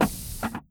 moleLiftMechanism.wav